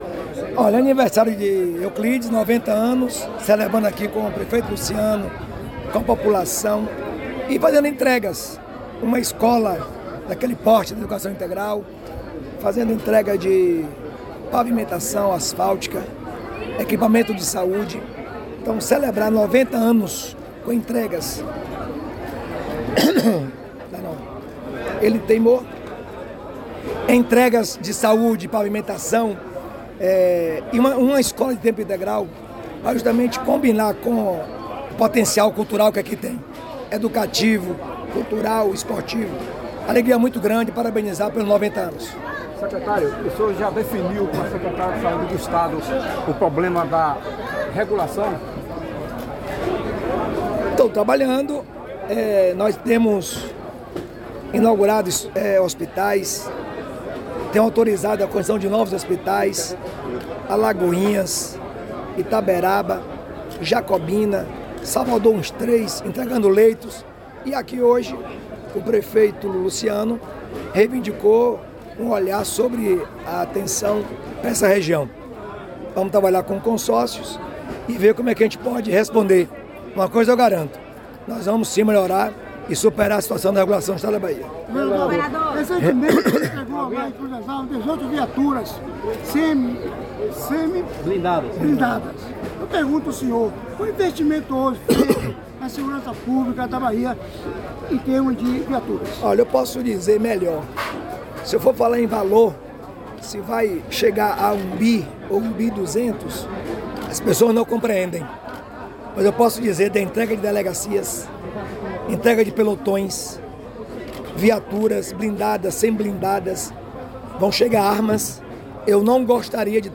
🎙Entrevista governador Jerônimo Rodrigues